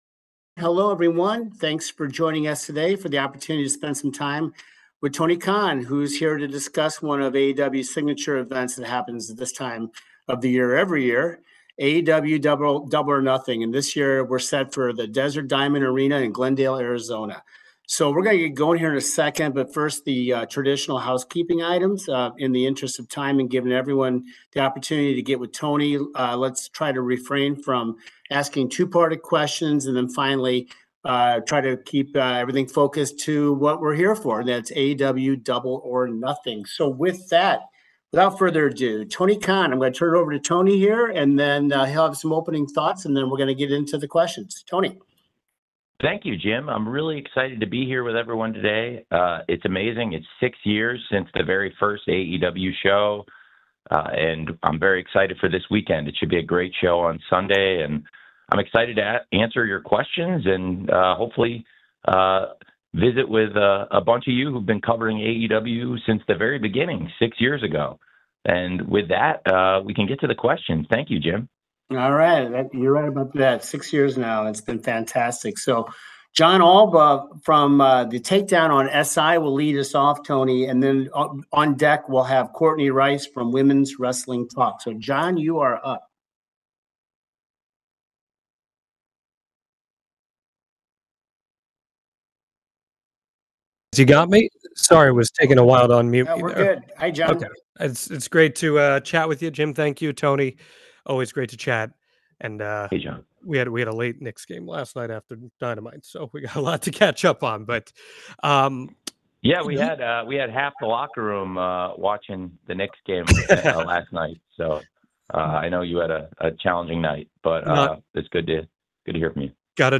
The media call with AEW's Tony Khan about Double or Nothing 2025, HBO MAX's impact on viewership, creative improvements and more.
Tony Khan, the President, General Manager and Head of Creative for All Elite Wrestling, sat down with the media to discuss the 6th Double or Nothing emanating from the Desert Diamond Arena in Glendale, Arizona this Sunday May 25, 2025.